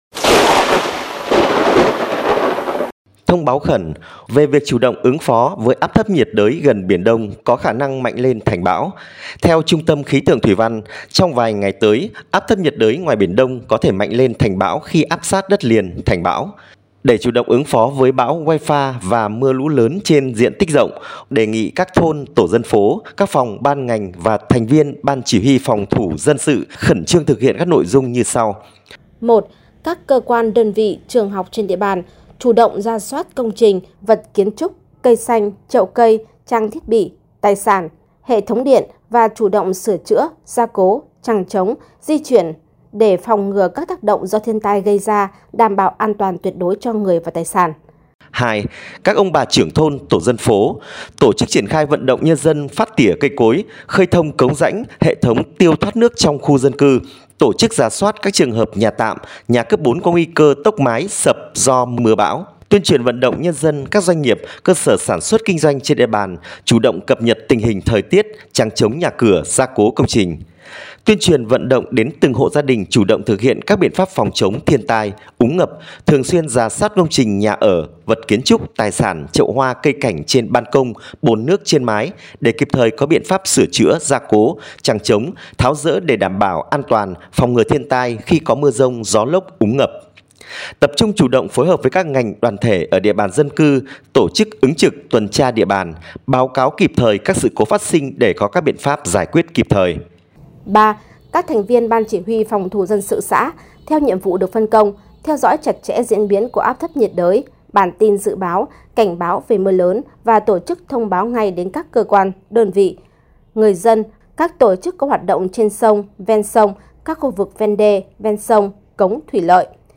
File phát thanh Thông báo khẩn về việc chủ động ứng phó với áp thấp nhiệt đới gần biển đông có khả năng mạnh lên thành bão